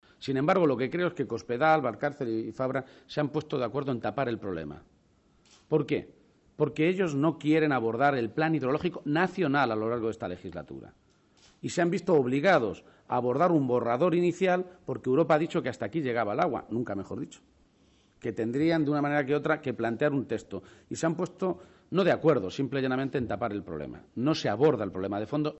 García-Page se pronunciaba de esta manera en una rueda de prensa conjunta, en Valencia, junto al secretario general del PSOE valenciano, en la capital de la comunidad vecina.
Cortes de audio de la rueda de prensa